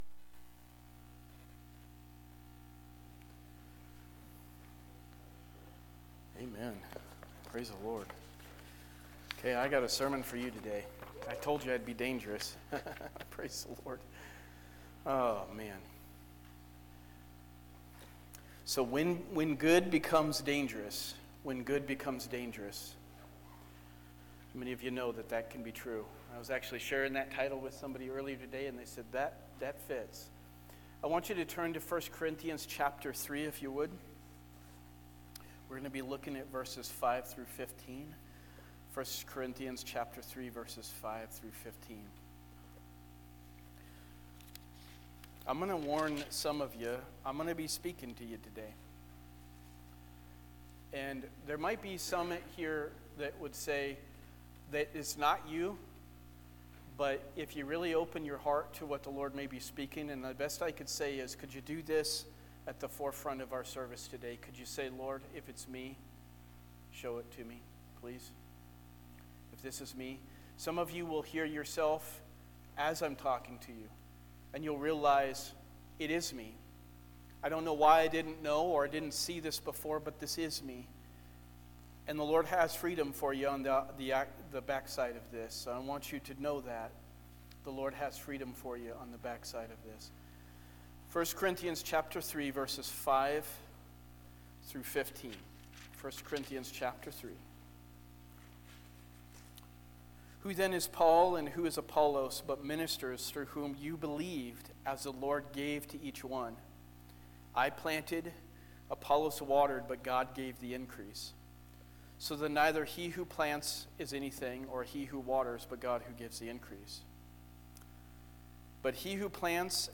Sermons by Abundant Life Assembly